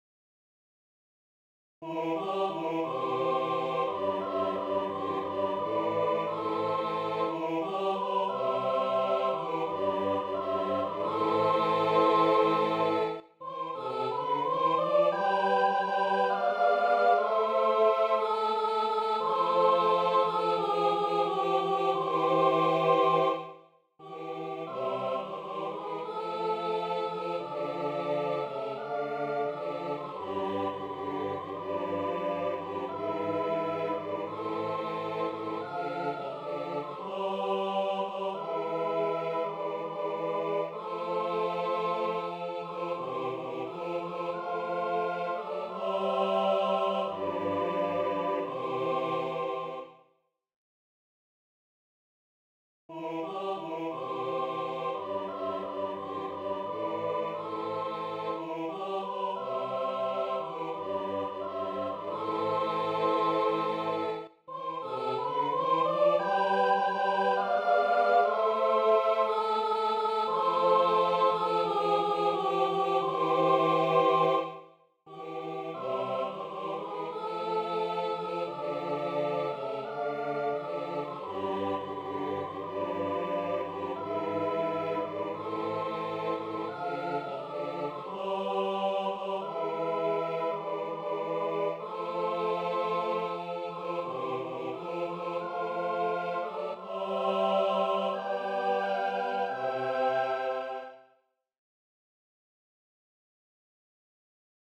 Мужской хор (до-мажор и си-ь мажор)